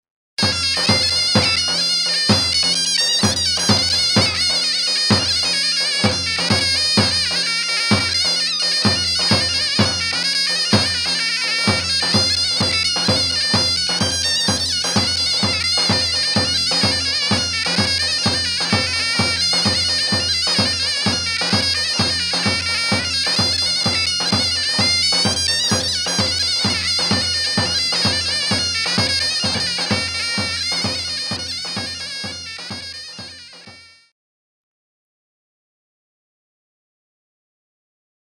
Kategori Sözlü, Sözsüz Yöresel Müzikler